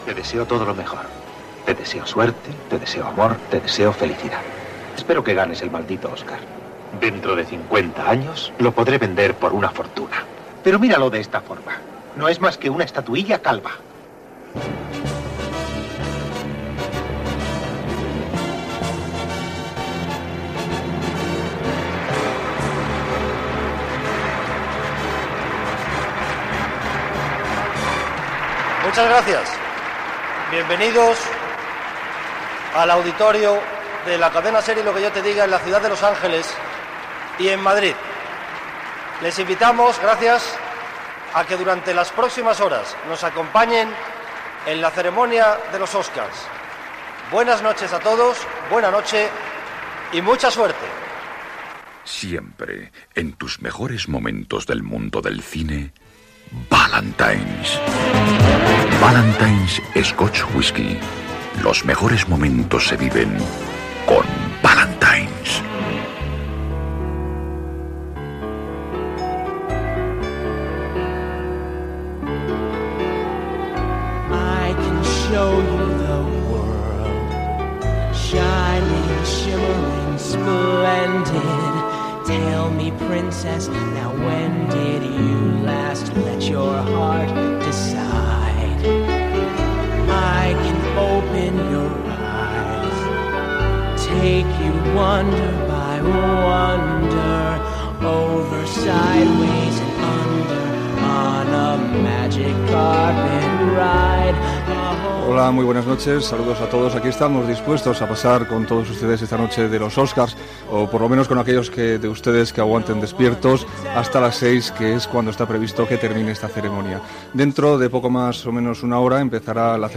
Espai especial per informar, des del Dorothy Chandler Pavilion de Los Angeles, de la cerimònia de lliurament dels premis Oscar de cinema.
Benvinguda i publicitat.